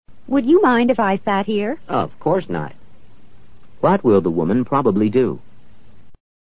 托福听力小对话【53】